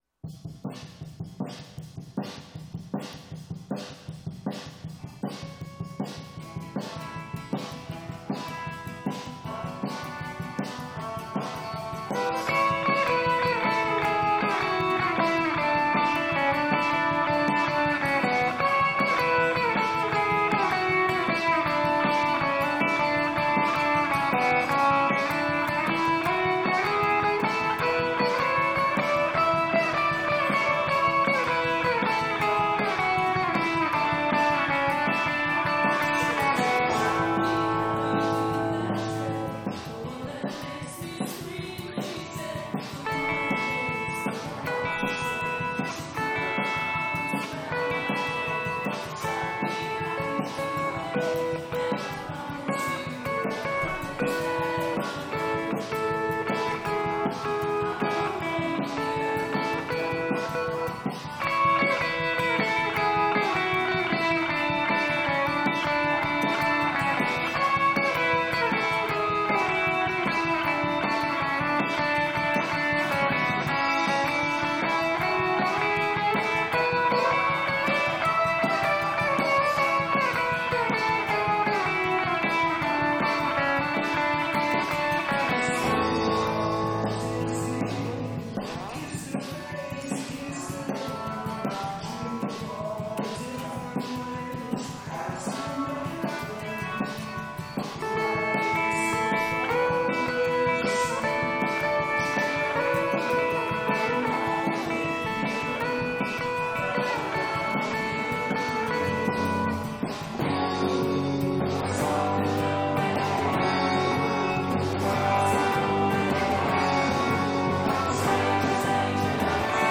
Smooth Rock & Natural Roll
electric guitar and vocals
accoustic guitar and vocals
bass and vocals
banjo
percussion
guitar and vocals